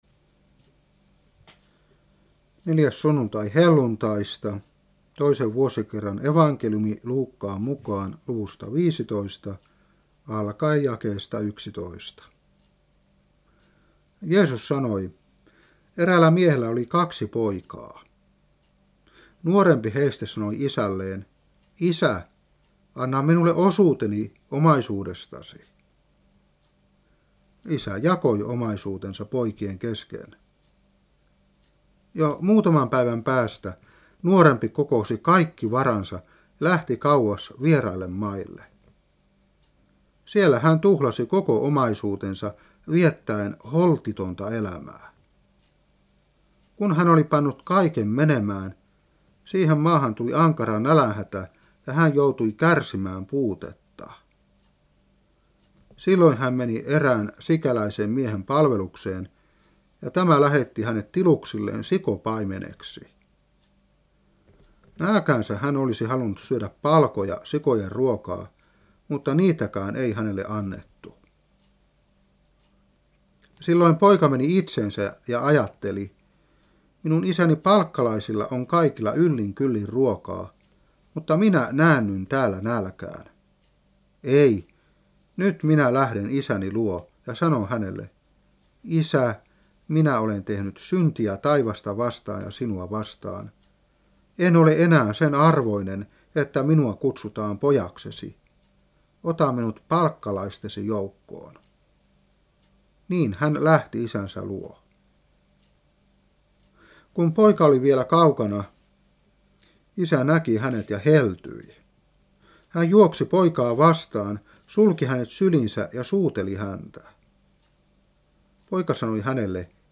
Saarna 1994-6. Luuk.15:11-32.